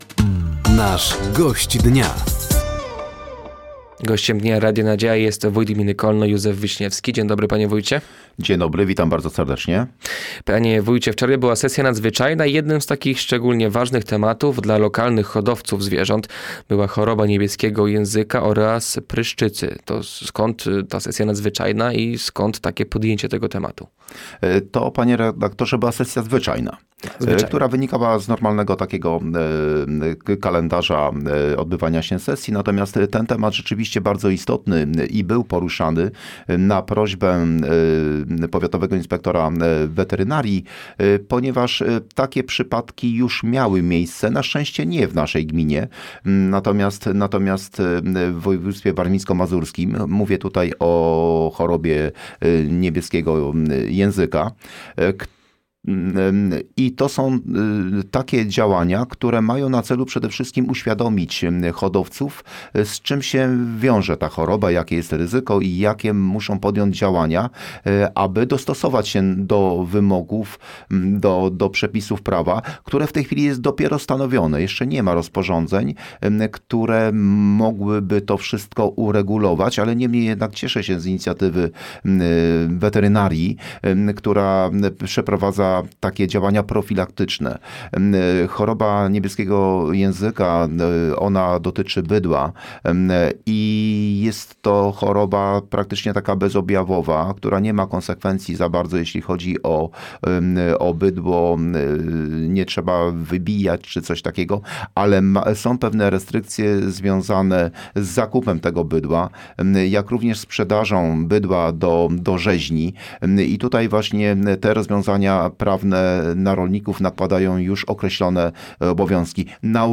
Gościem Dnia Radia Nadzieja był wójt gminy Kolno Józef Wiśniewski. Tematem rozmowy były istotne kwestie dotyczące choroby niebieskiego języka oraz pryszczycy, Centrum Opiekuńczo-Mieszkalne w Glinkach oraz problemy demograficzne.